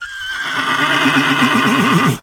horse-whinny-2.ogg